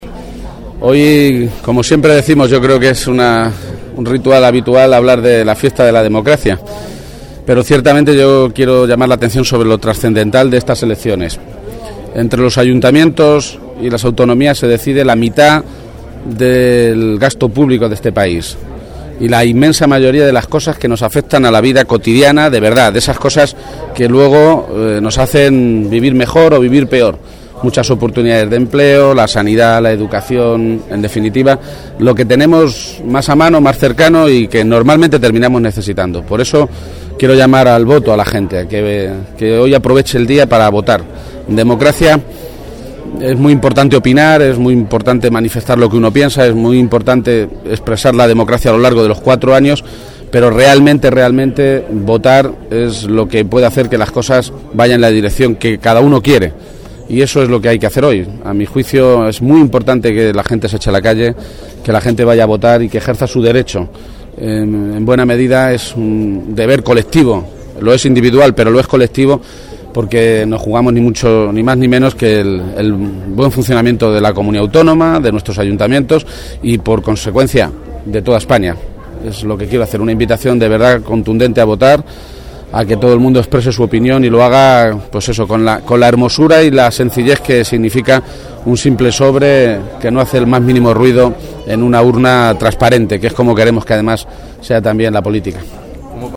El candidato a la Presidencia de Castilla-La Mancha, Emiliano García-Page, tras ejercer su derecho a voto esta mañana, ha querido llamar la atención sobre lo trascendental de las elecciones autonómicas y municipales que se celebran hoy y ha hecho un llamamiento y una invitación contundente a todos los ciudadanos a ir a votar.
Audio García-Page tras votación